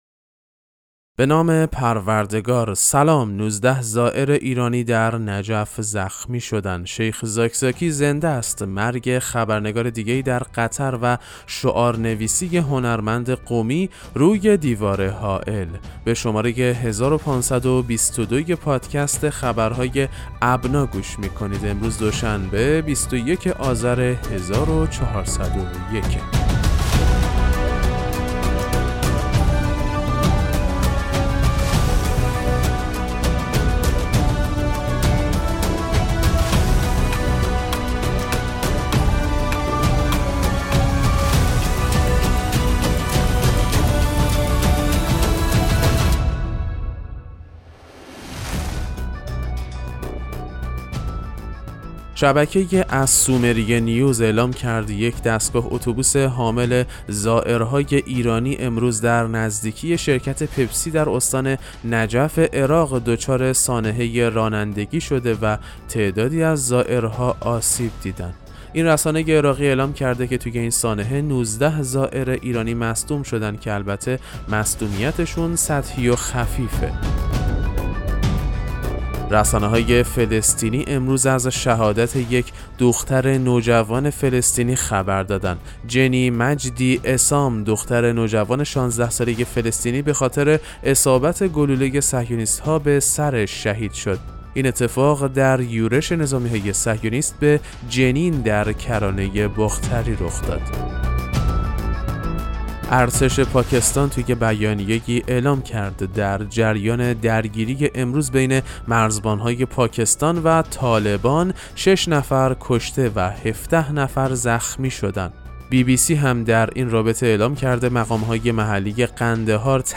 پادکست مهم‌ترین اخبار ابنا فارسی ــ 21 آذر 1401